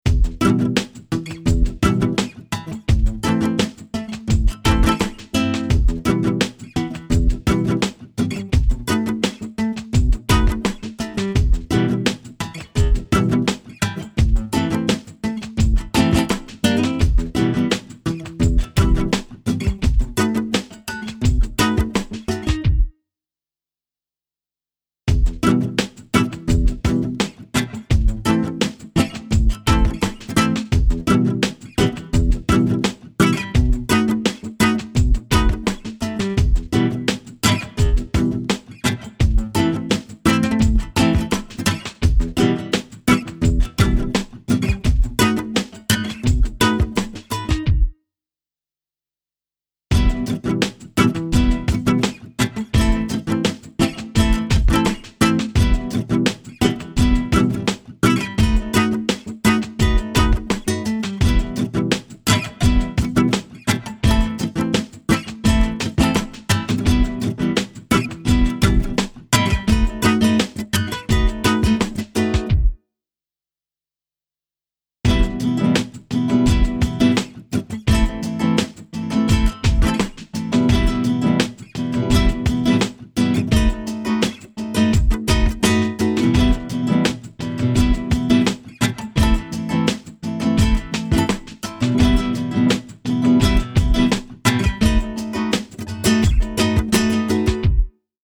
NG_LaidBack_85bpm_Drums.mp3